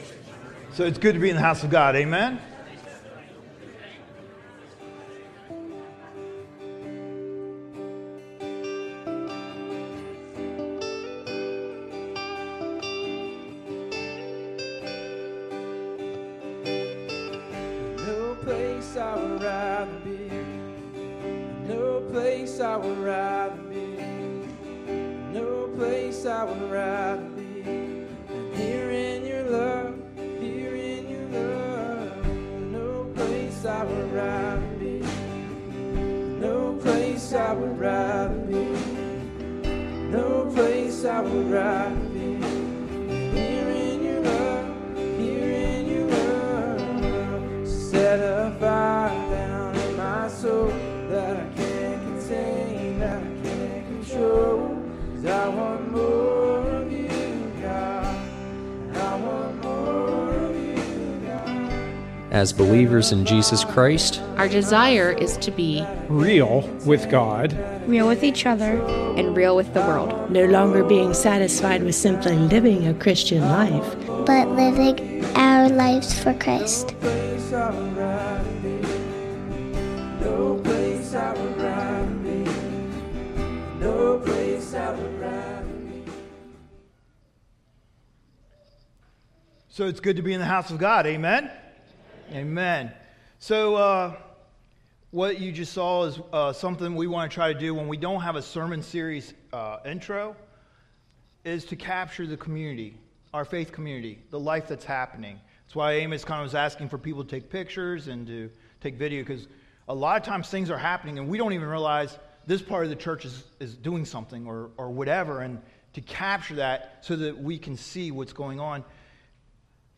Baby Dedication Service - Mercersburg Mennonite Church